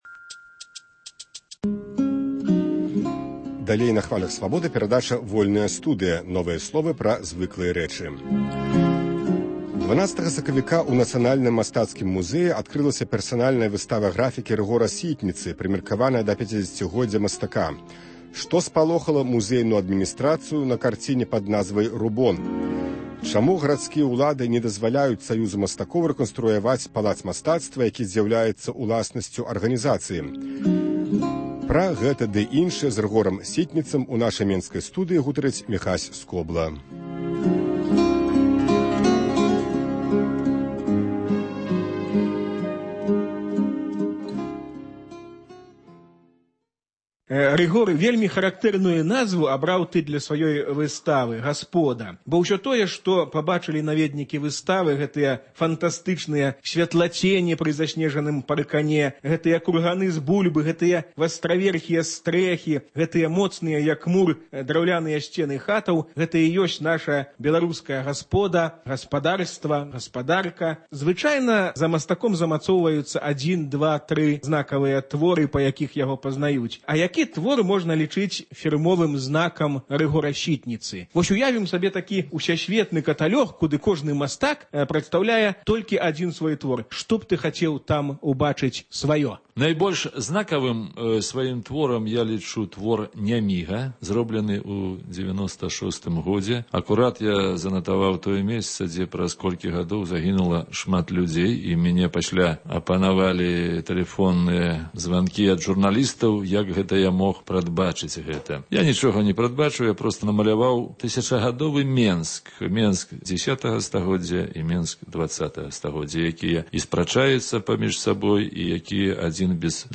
гутарыць з мастаком